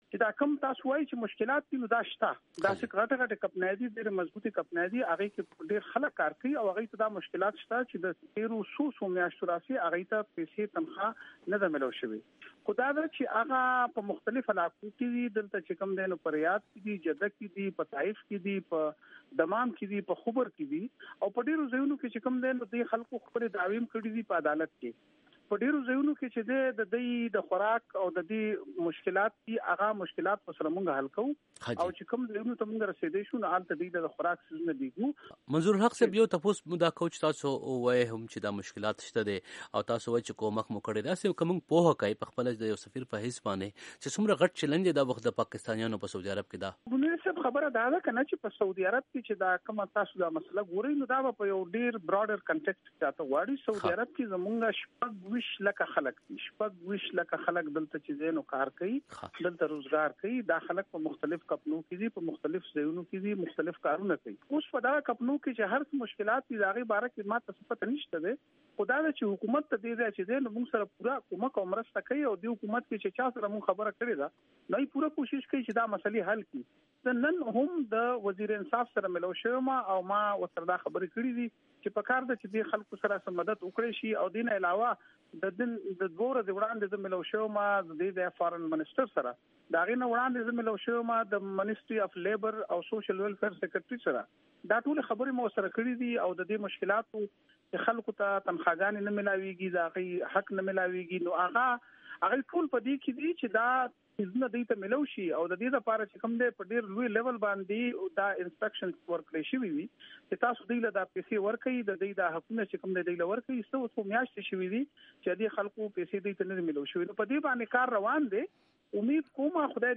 په سعودي کې د پاکستان سفير ښاغلي منظورالحق مرکه